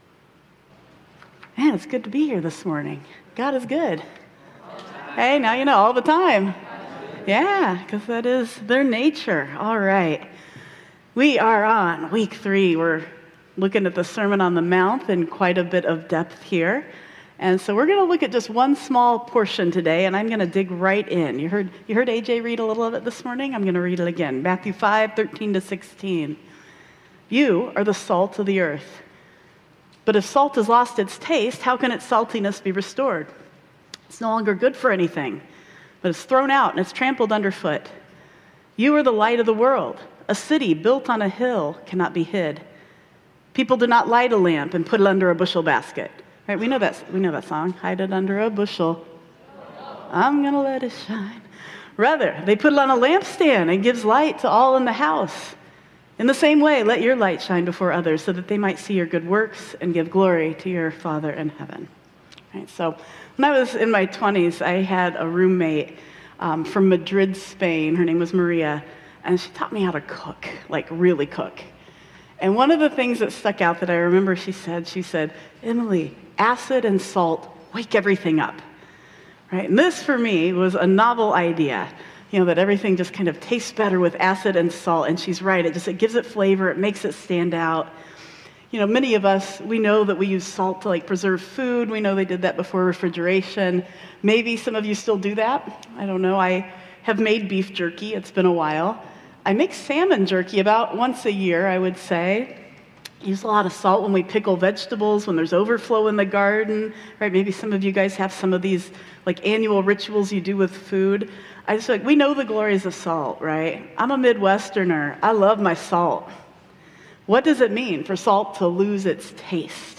28 Sep 2025 | Sermon On the Mount: Salt, Light, Deeds